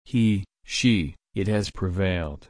/pɹɪˈveɪl/